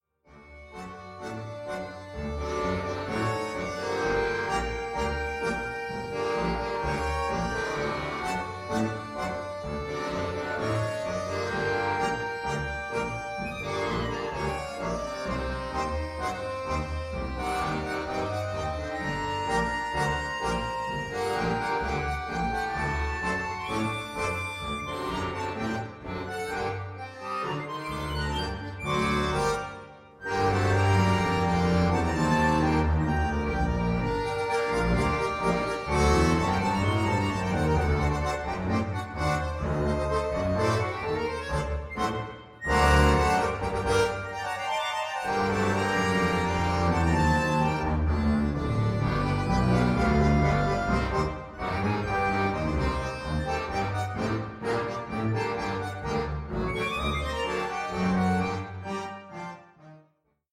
als Arrangement für Akkordeonorchester
Evergreen, Tango